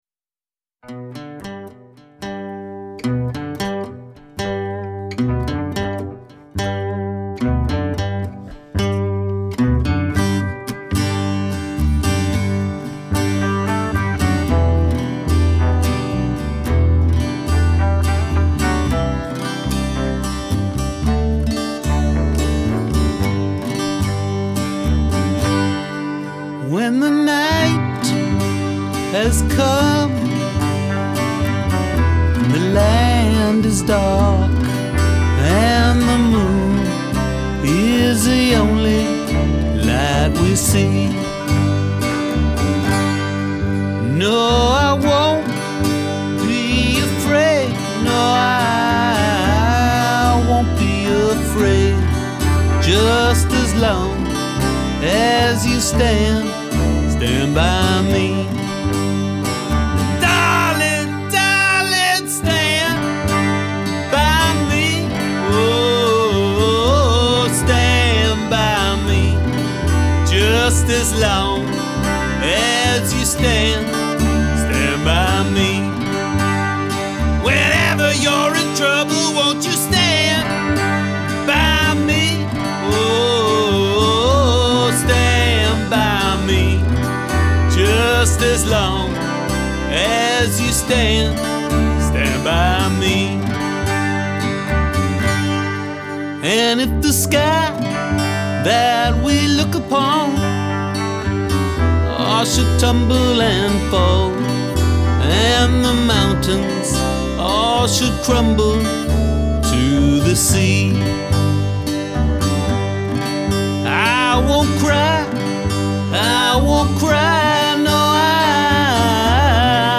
A classic – one of the great feel good songs of all time.
vocals
guitar
Highland pipes & midi whistle
bass
drums